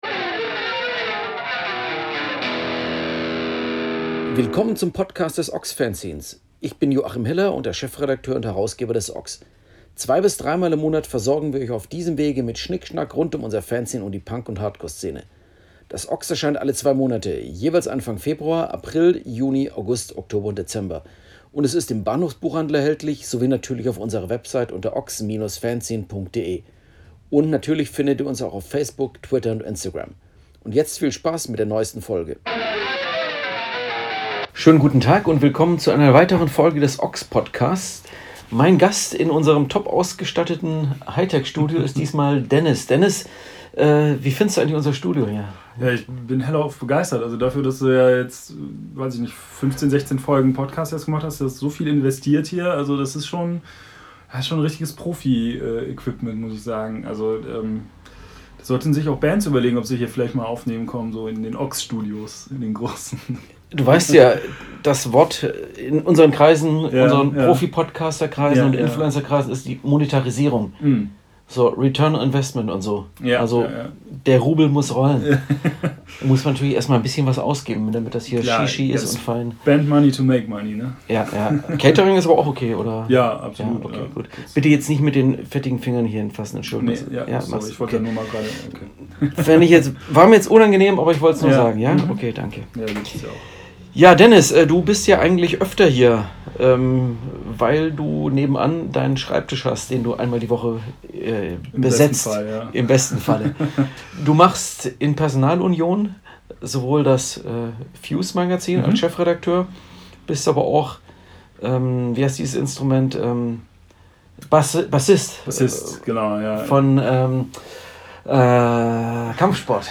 Reichlich Themen also für ein Gespräch